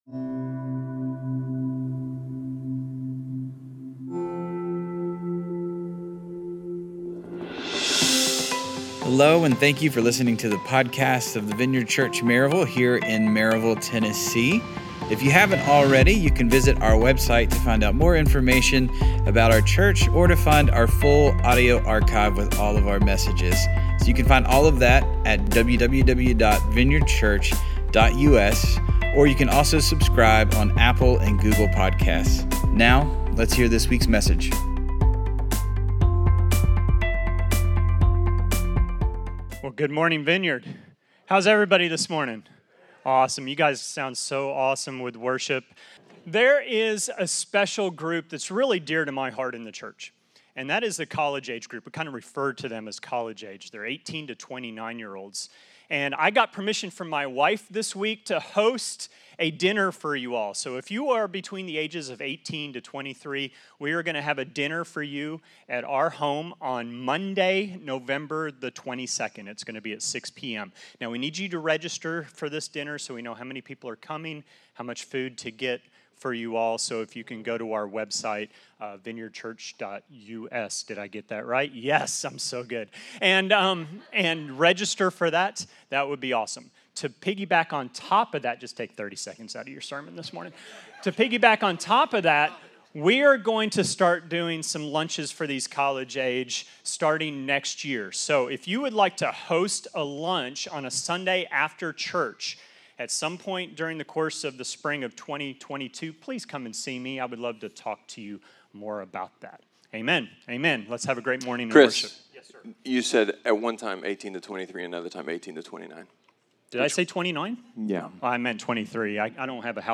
A sermon about a far-fetched, unattainable, ‘too-good-to-be-be-true’ spiritual reality… that’s actually not far-fetched, is certainly attainable, and is altogether true.